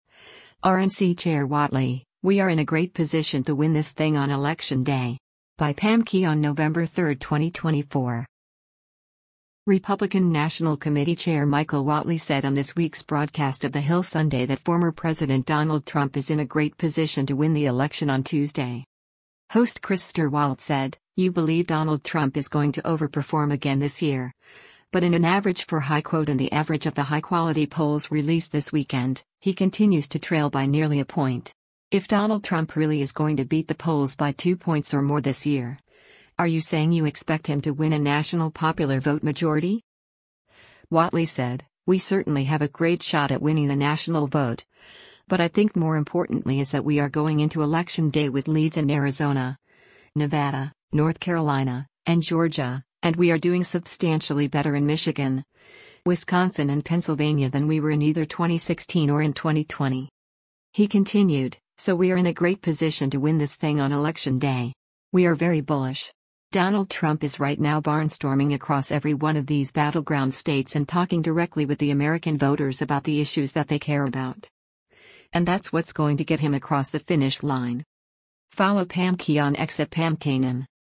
Republican National Committee Chair Michael Whatley said on this week’s broadcast of “The Hill Sunday” that former President Donald Trump is in a great position to win the election on Tuesday.